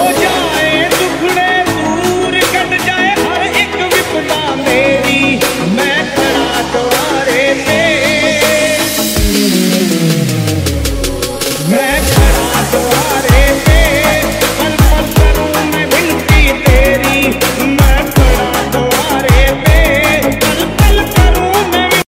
Devotional Ringtones
Remix Ringtones